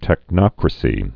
(tĕk-nŏkrə-sē)